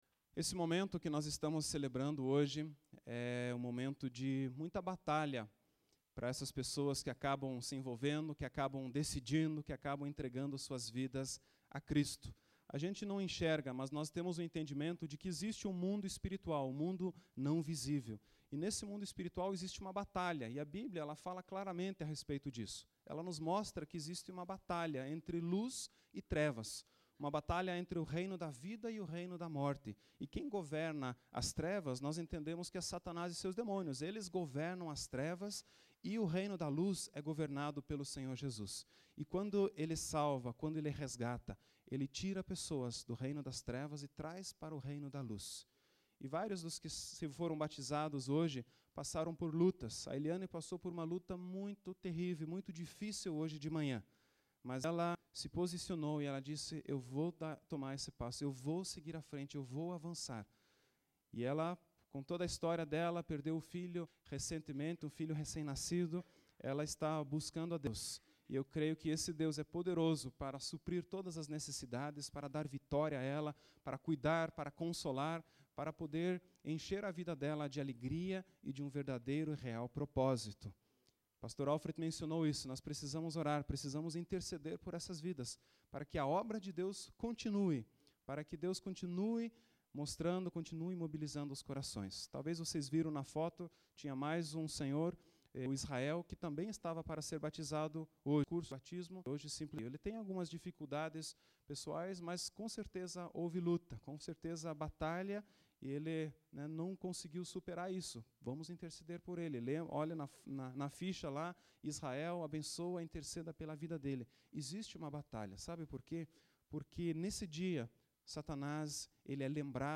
Culto de BATISMO e Episódio 4 de AMISTAD - SERVOS: Obra de Arte.